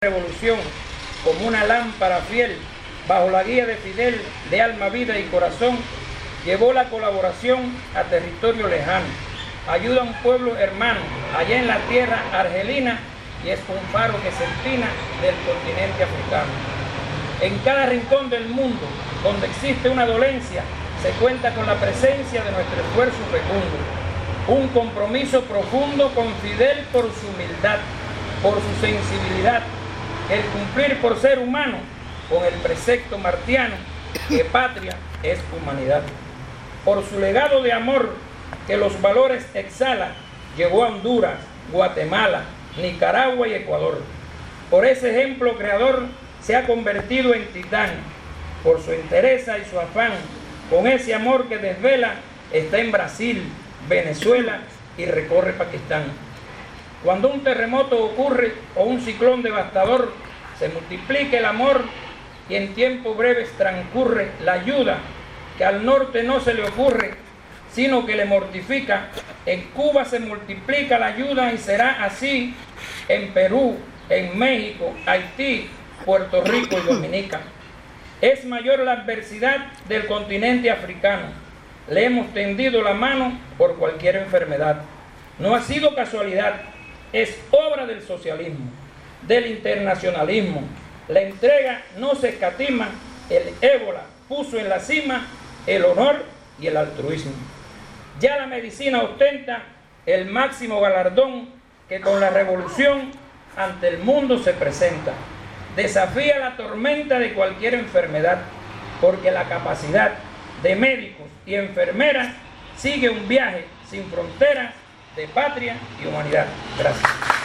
Como emotivo y solemne homenaje al Comandante en Jefe Fidel Castro, en el primer aniversario de su desaparición física, sesionó hoy el espacio de reflexión En nombre de la paz, en la Delegación granmense del Instituto Cubano de Amistad con los pueblos.